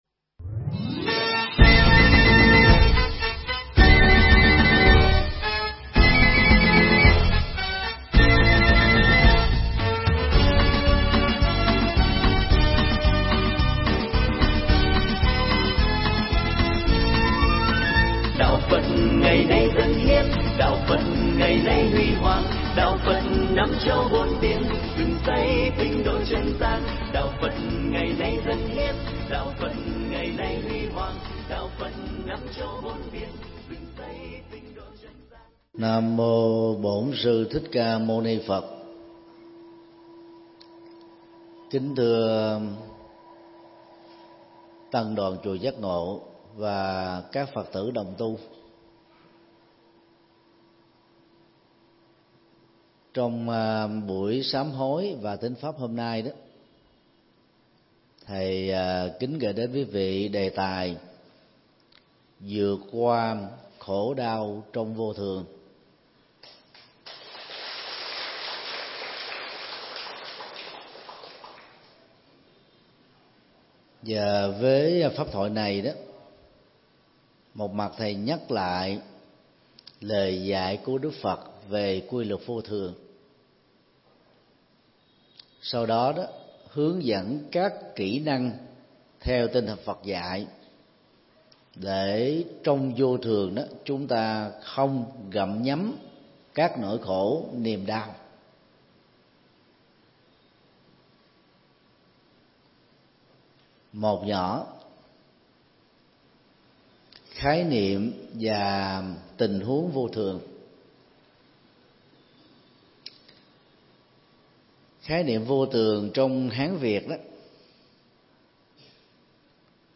Mp3 Thuyết Giảng Vượt Qua Khổ Đau Trong Vô Thường – Thượng Tọa Thích Nhật Từ giảng tại chùa Giác Ngộ, ngày 26 tháng 7 năm 2018